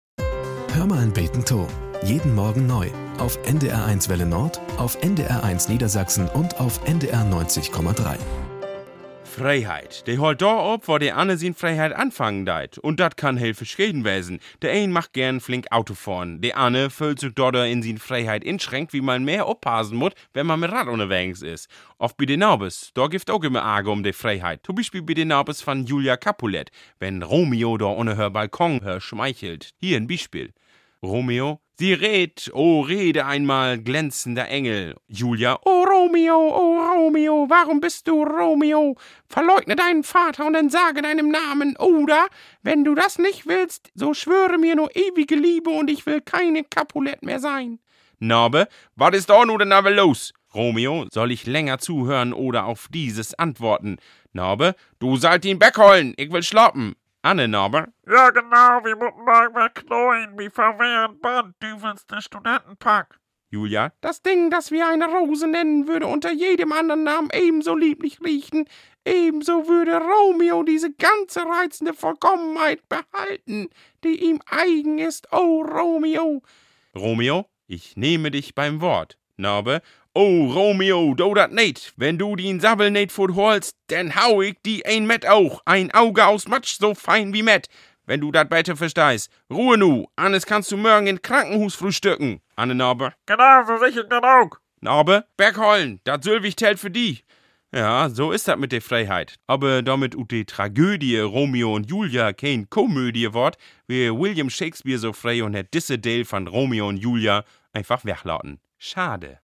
Nachrichten - 27.06.2023